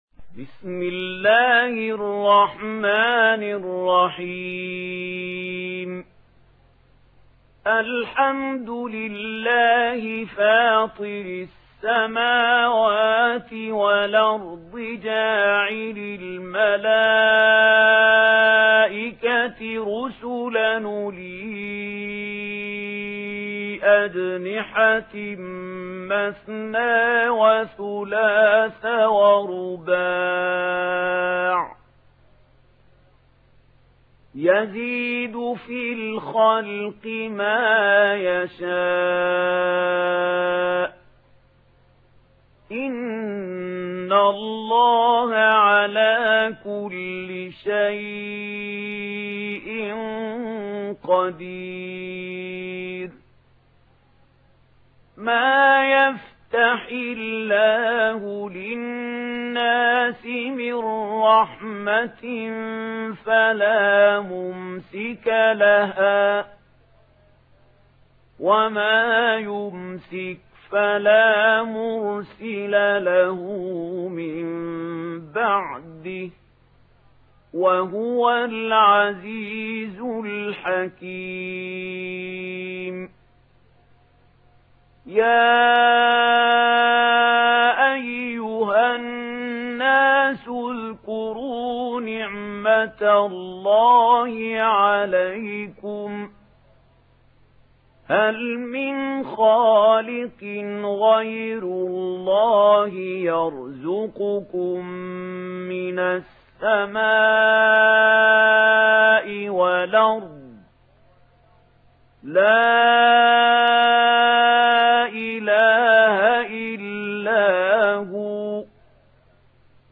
دانلود سوره فاطر mp3 محمود خليل الحصري روایت ورش از نافع, قرآن را دانلود کنید و گوش کن mp3 ، لینک مستقیم کامل